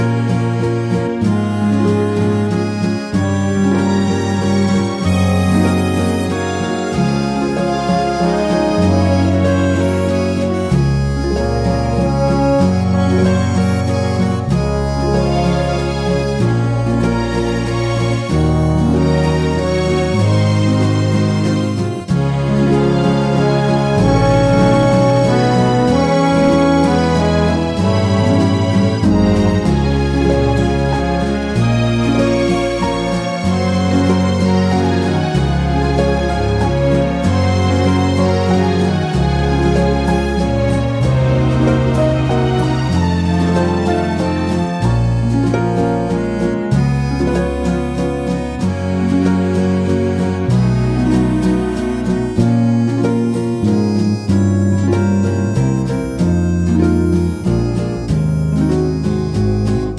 no Backing Vocals Crooners 2:44 Buy £1.50